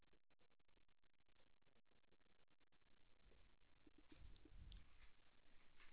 osa 0918 (Monaural AU Sound Data)